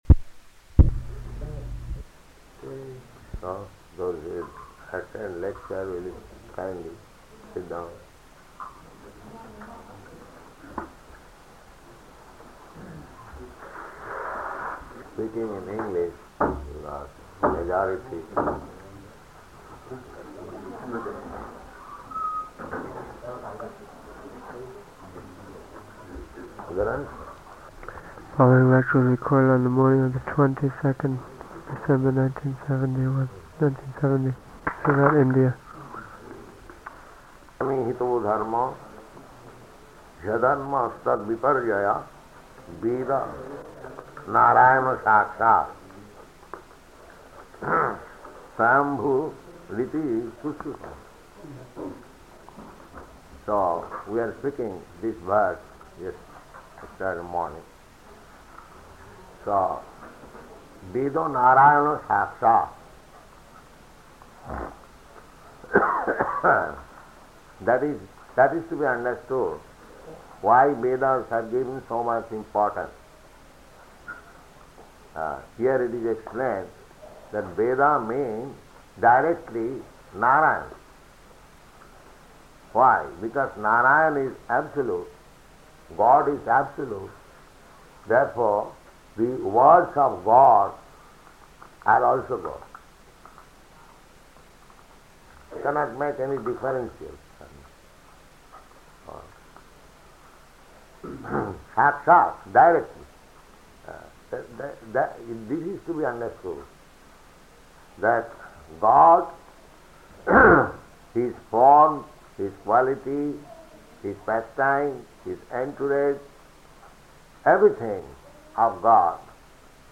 Śrīmad-Bhāgavatam 6.1.40 --:-- --:-- Type: Srimad-Bhagavatam Dated: December 22nd 1970 Location: Surat Audio file: 701222SB-SURAT.mp3 Prabhupāda: So those who attend lecture will kindly sit down.